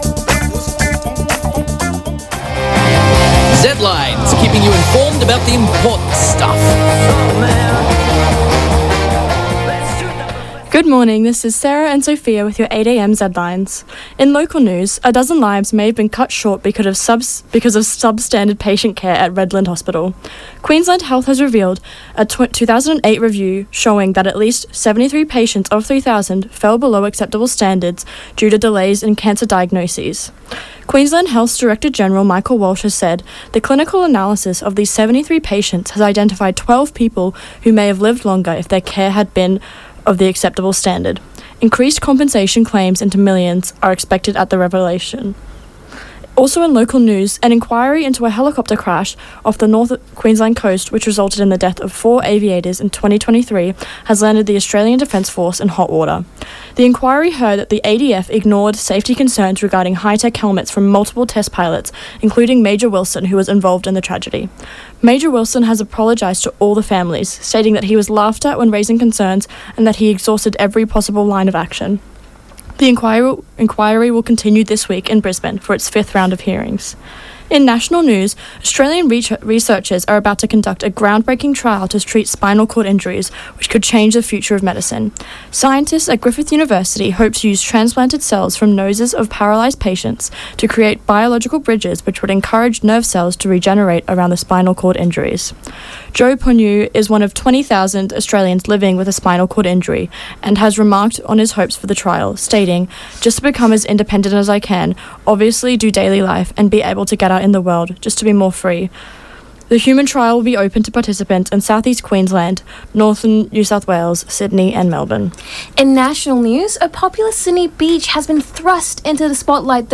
Zedlines Bulletin 21.10 8 am.mp3 (4.31 MB)